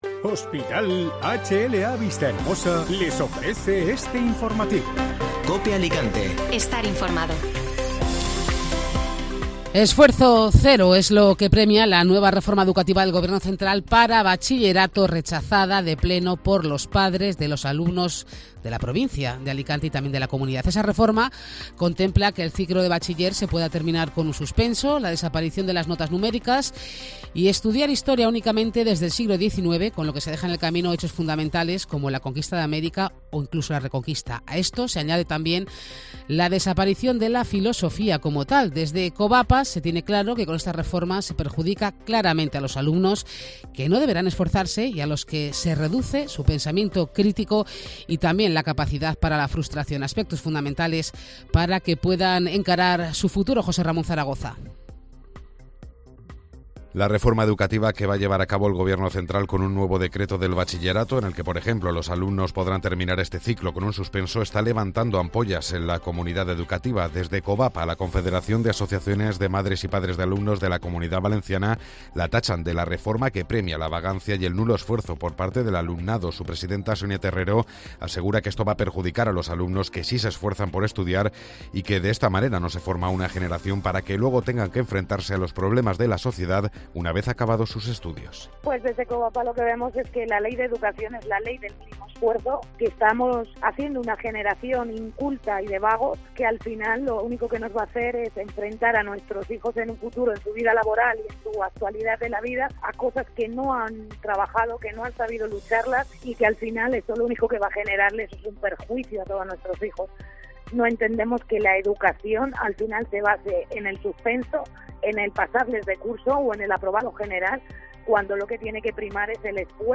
Informativo Mediodía COPE (Jueves 7 de abril)